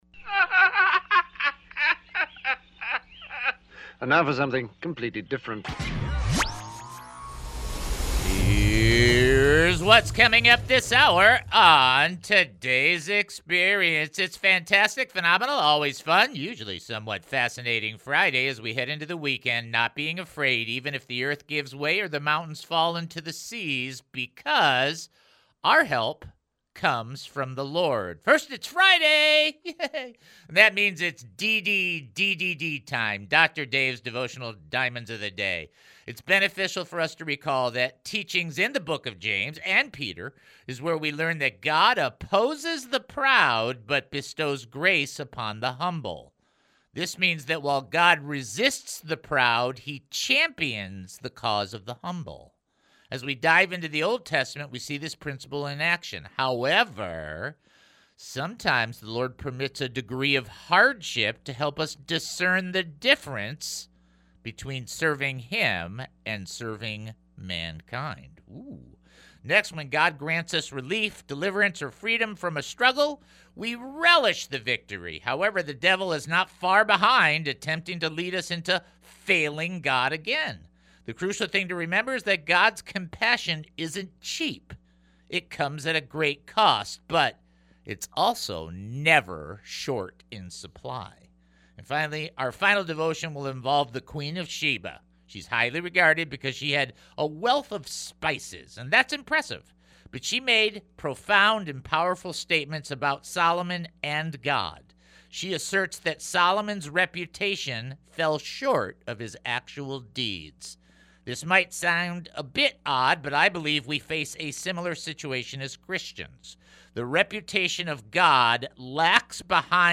C) It's a plethora of prayers and praise reports as the callers' help direct the show under the guidance of the Holy Spirit.